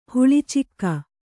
♪ huḷi cikka